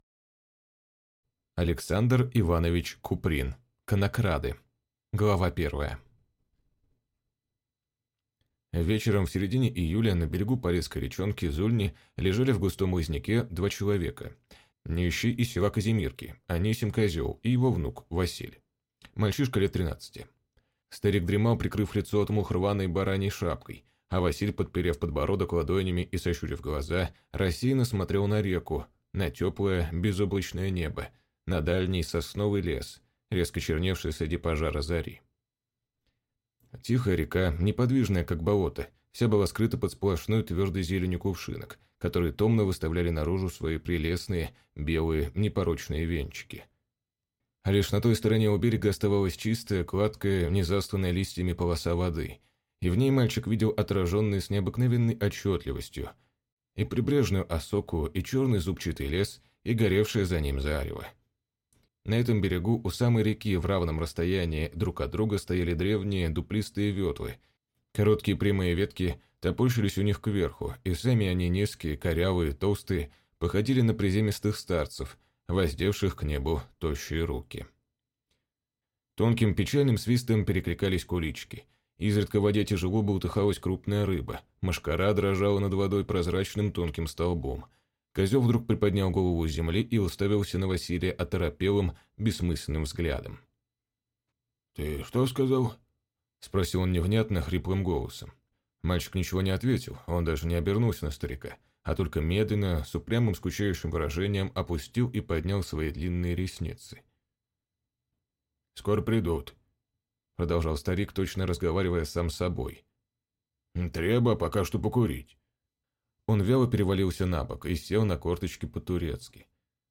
Аудиокнига Конокрады | Библиотека аудиокниг